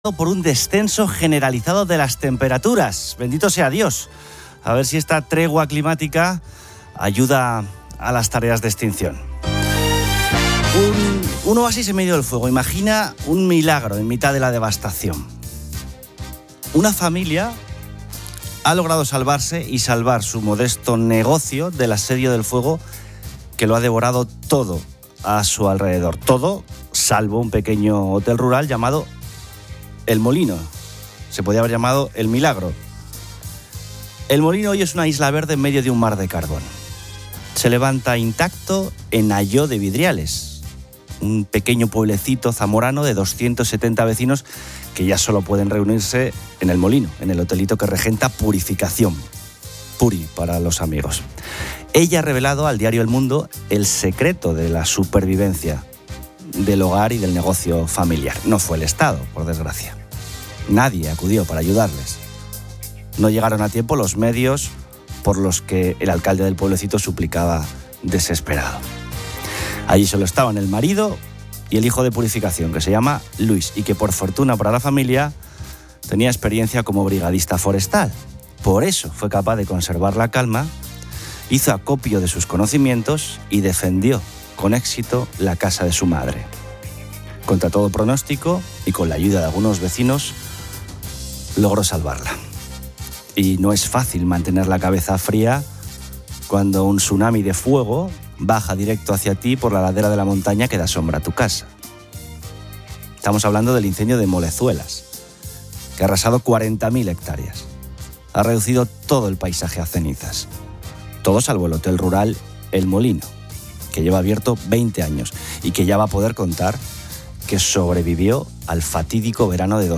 El locutor sugiere que el chantaje de Puigdemont es el "guion" de la legislatura.